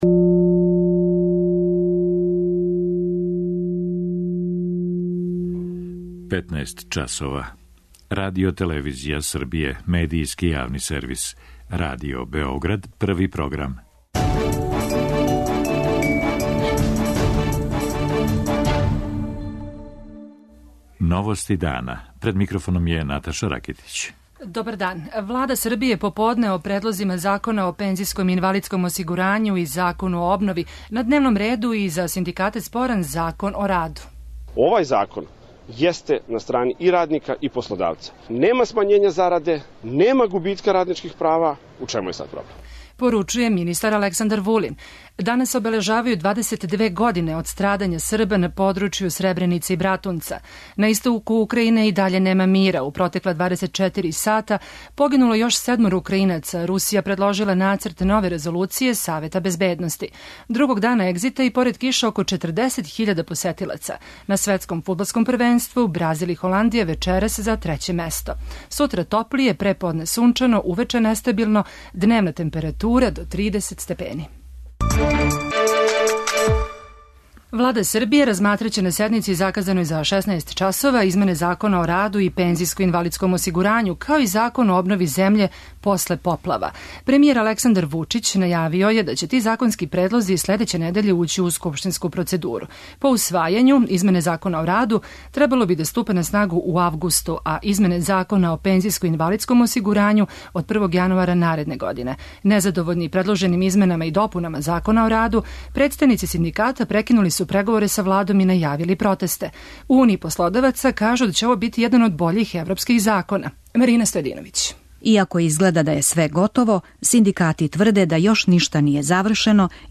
Саговорник Новости дана је председник општине Крупањ Раде Грујић.
преузми : 14.96 MB Новости дана Autor: Радио Београд 1 “Новости дана”, централна информативна емисија Првог програма Радио Београда емитује се од јесени 1958. године.